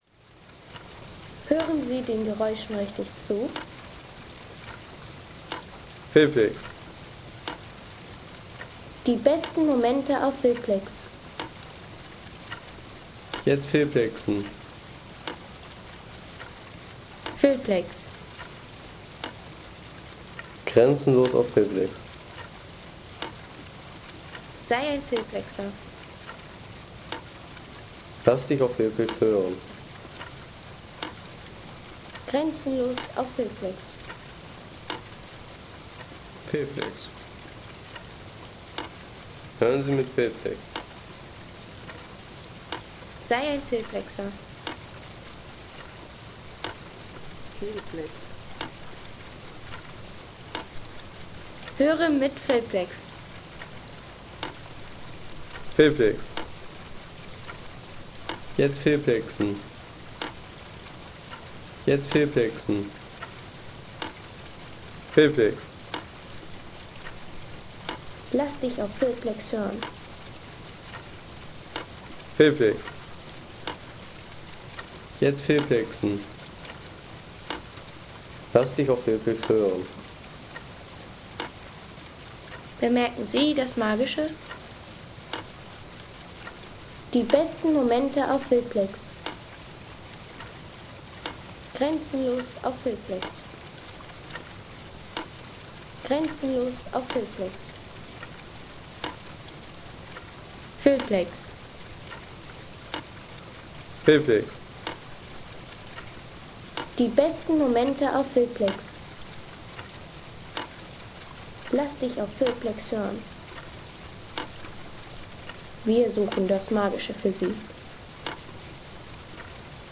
Schwanzhammer/Fallhammer
Technik - Hämmern